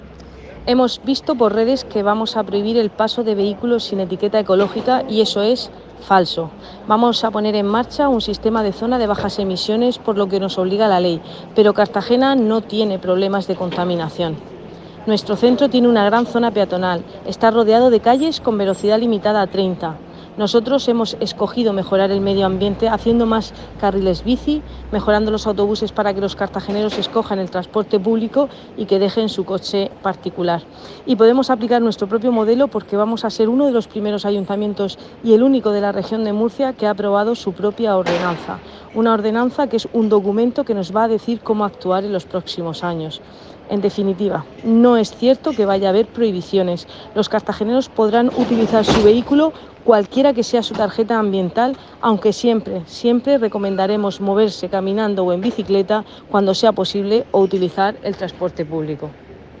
Enlace a Declaraciones de Cristina Mora